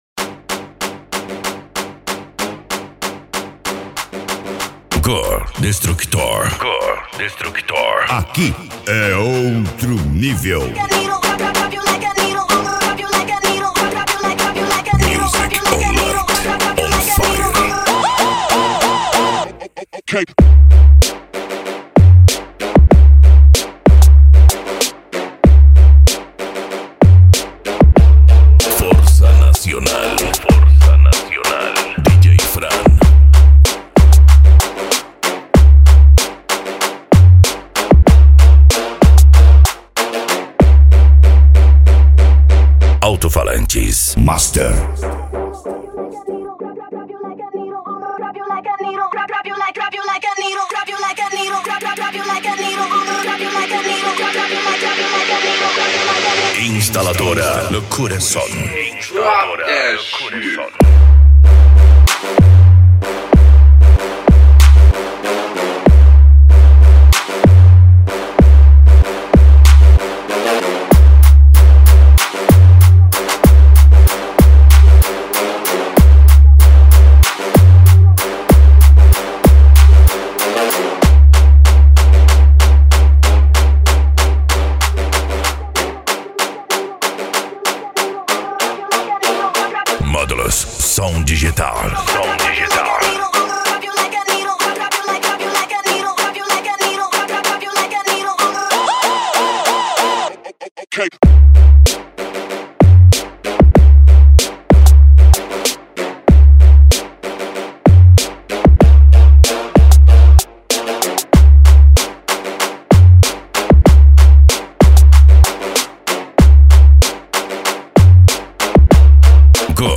Bass
Funk
Remix